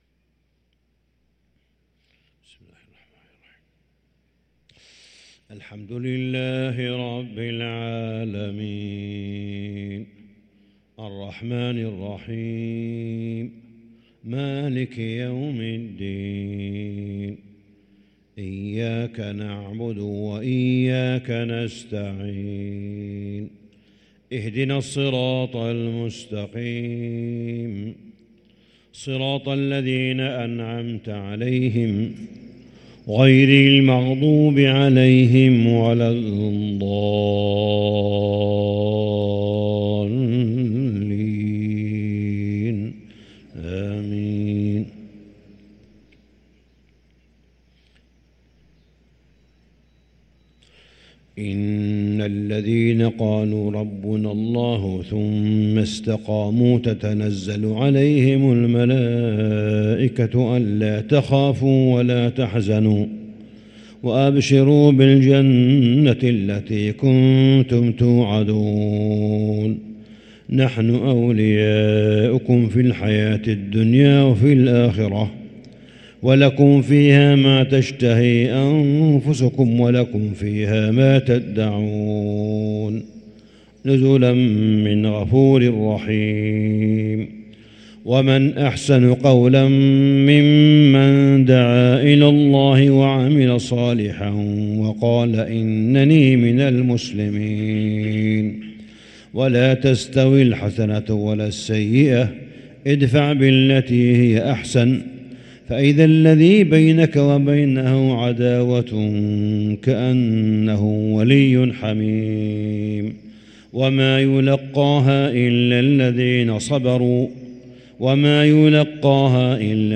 صلاة الفجر للقارئ صالح بن حميد 22 رمضان 1444 هـ
تِلَاوَات الْحَرَمَيْن .